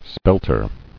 [spel·ter]